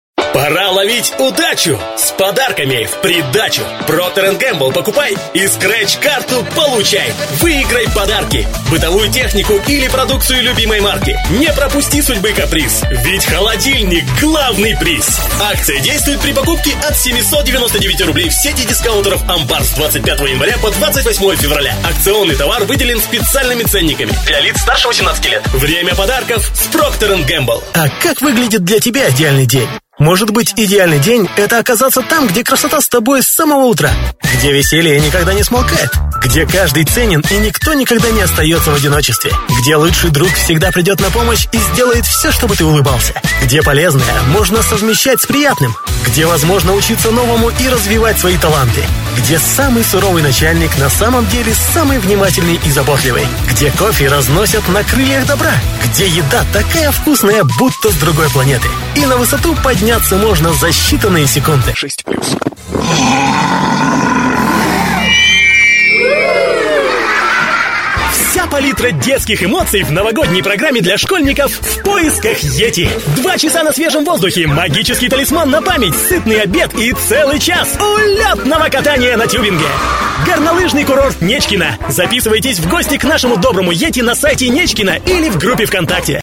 Молодой энергичный голос.
Тракт: микрофон RODE NTK, предусилитель dbx-376, карта TC Impact Twin(FireWire), Акустическая кабина(Mappysil пирамидки)